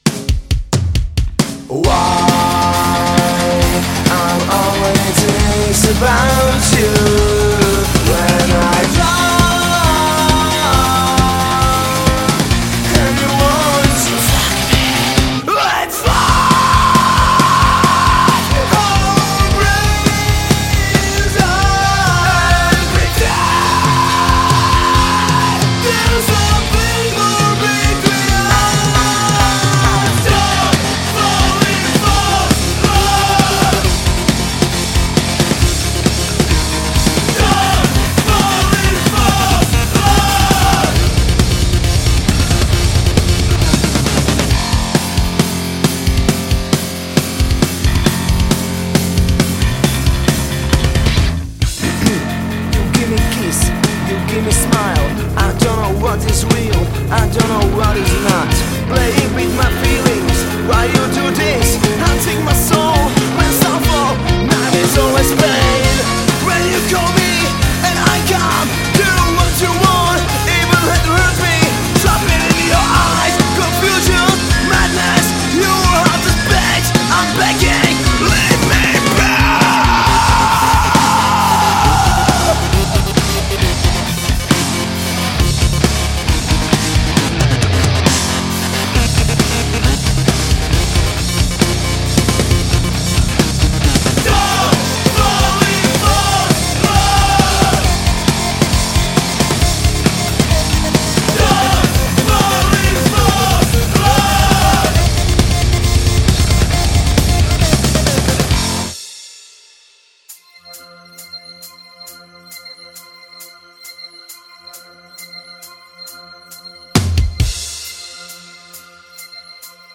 Žánr: Metal/HC
Dance metal s prvky elektronické hudby a moderního metalu.